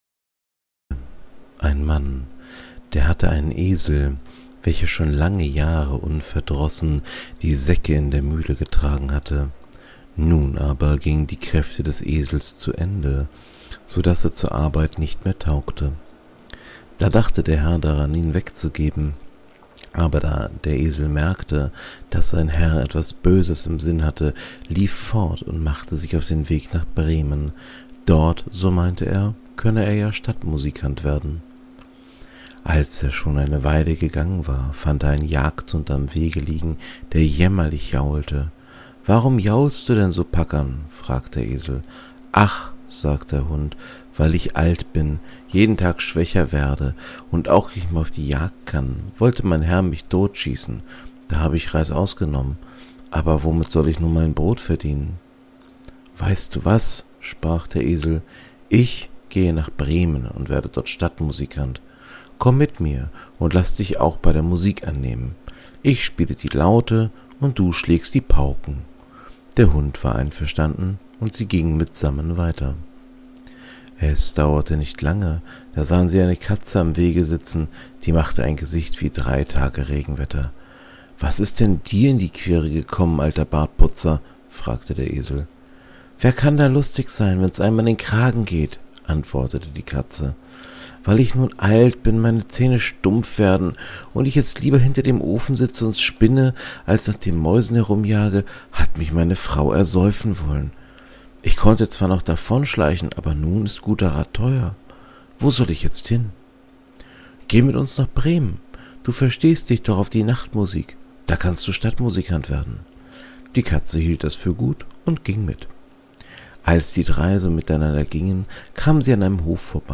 In diesem kleinen Podcast Projekt lese ich Märchen vor. Dabei nutze ich die Texte aus dem Projekt Gutenberg.